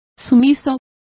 Complete with pronunciation of the translation of unresisting.